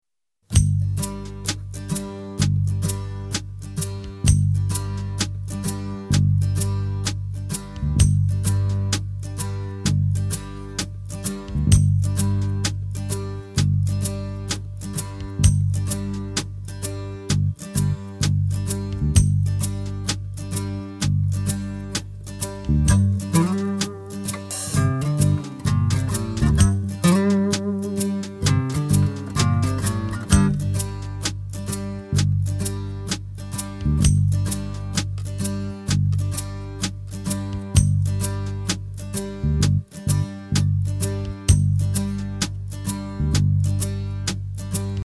Listen to the instrumental track.